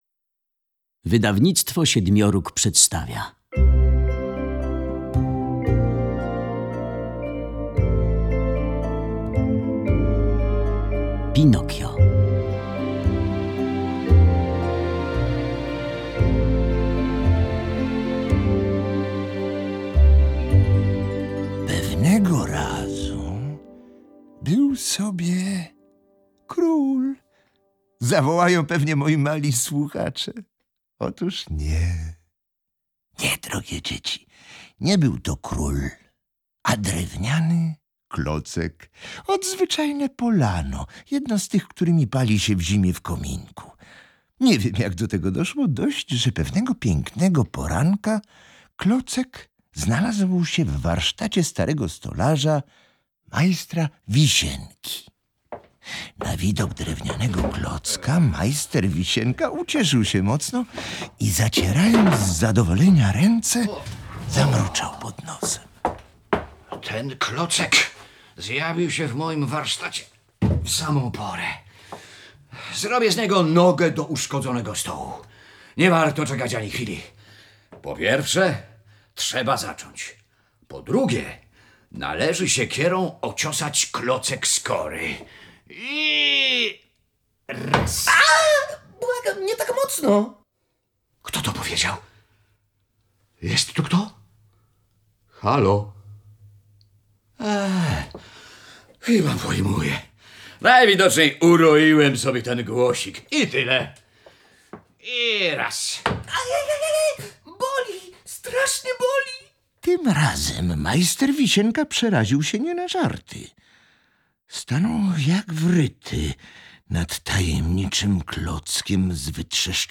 inscenizacji audio wykonanej przez Teatr Polskiego Radia w Warszawie